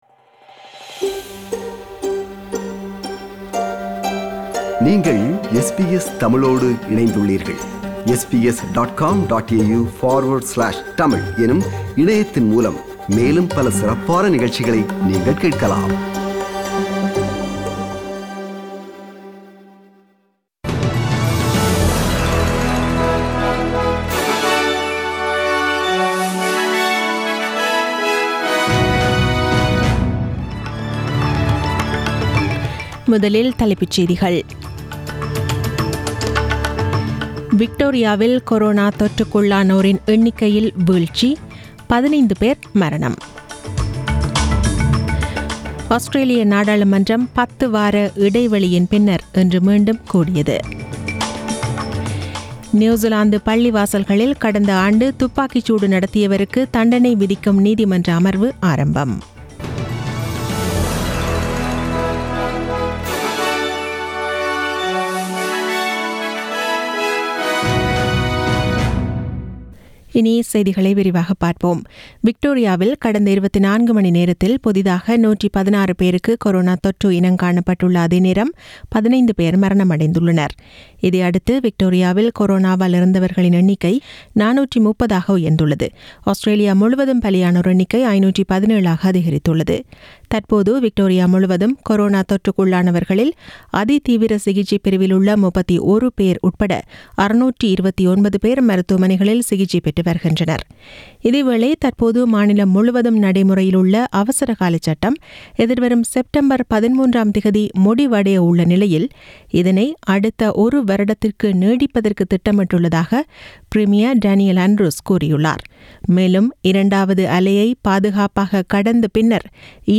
The news bulletin was aired on 24 August 2020 (Monday) at 8pm.